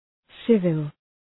Προφορά
{‘sıvəl}